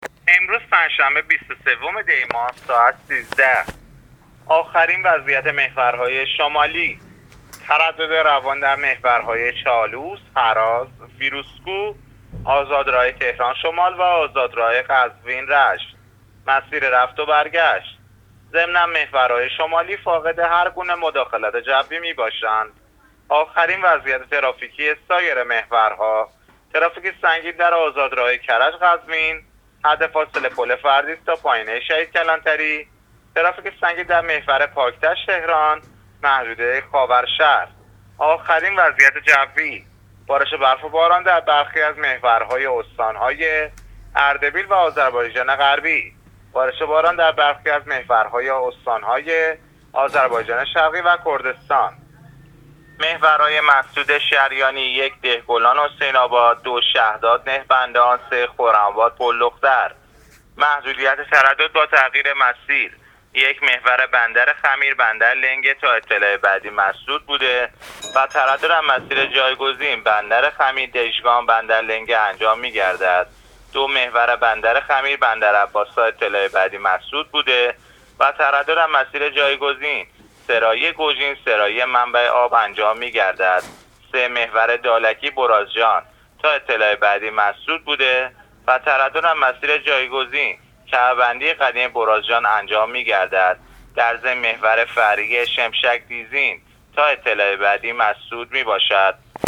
گزارش رادیو اینترنتی از آخرین وضعیت ترافیکی جاده‌ها تا ساعت ۱۳ بیست‌وسوم دی؛